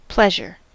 Ääntäminen
US : IPA : [ˈplɛʒ.ɚ]